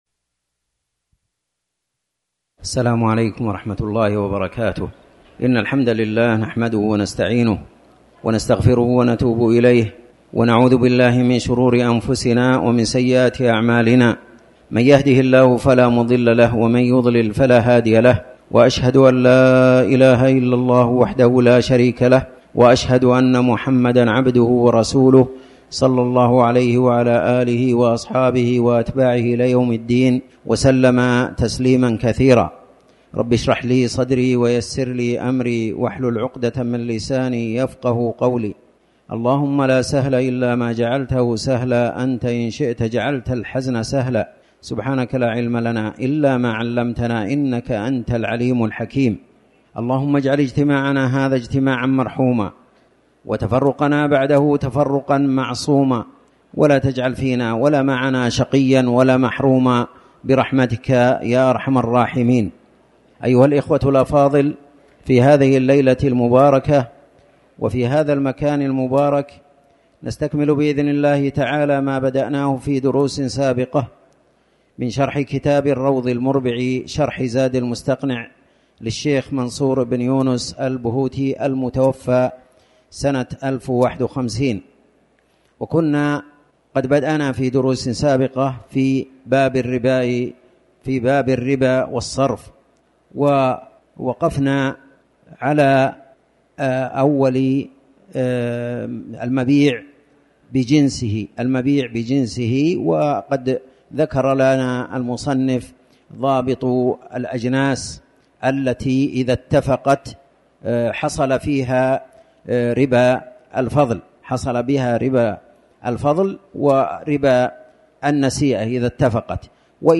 تاريخ النشر ٩ جمادى الأولى ١٤٤٠ هـ المكان: المسجد الحرام الشيخ